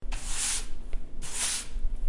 Sweeping.mp3